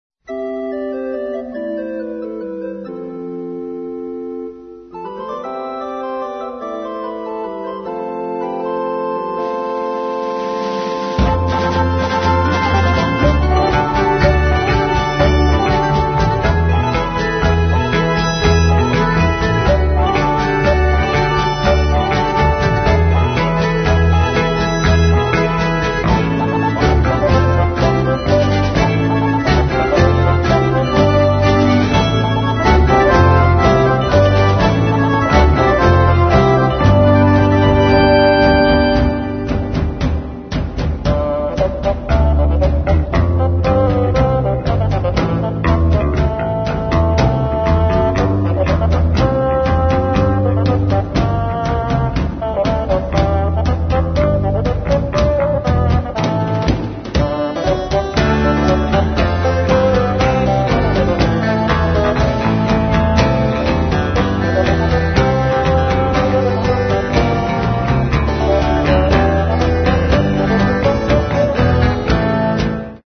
Mono, 1:13, 24 Khz, (file size: 215 Kb).